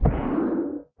guardian_hit1.ogg